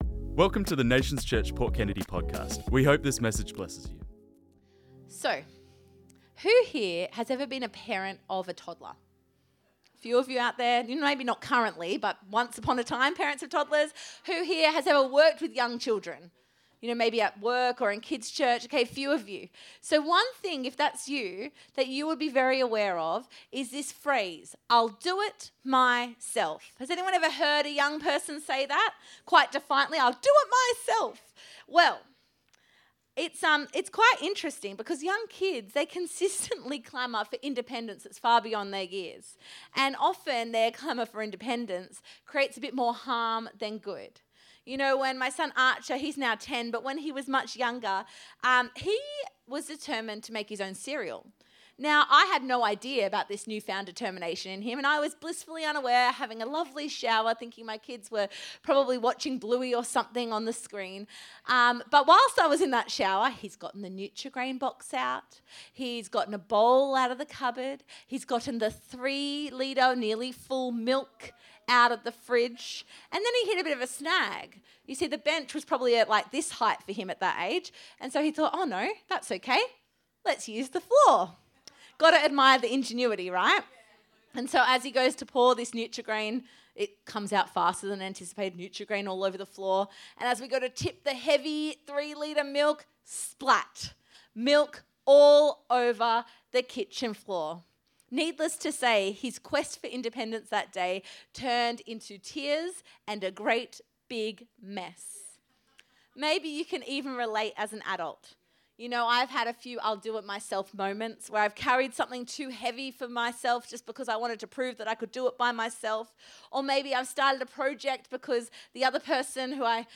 This message was preached on Sunday the 15th March 2026